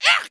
binf_damage.wav